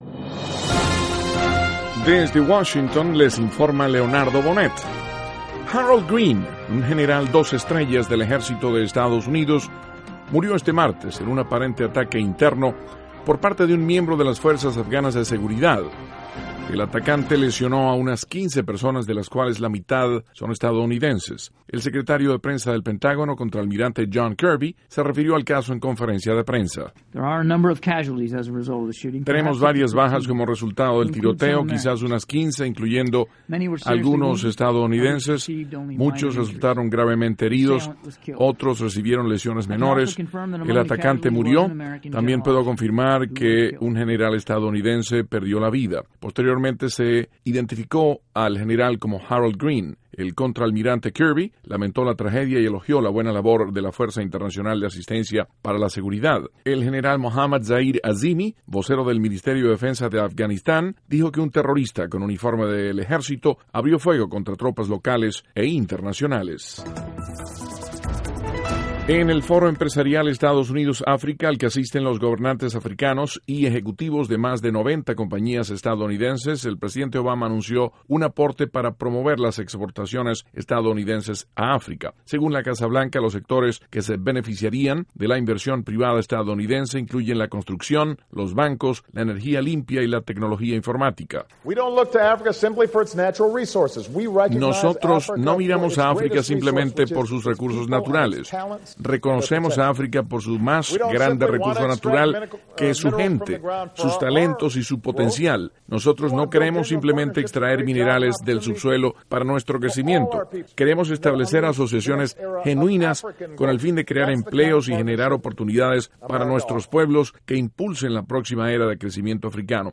NOTICIAS - MARTES, 5 DE AGOSTO, 2014
Duración: 3:28 Contenido: 1.- General estadounidense pierde la vida en Afganistán. (Sonido – Kirby – Pentágono) 2.- El presidente Obama anuncia 7 mil millones de dólares para promover exportaciones a África. (Sonido Obama) 3.- El Departamento de Estado ratifica que son 24 los funcionarios venezolanos con restricciones de visa. (Sonido – Jen Psaki)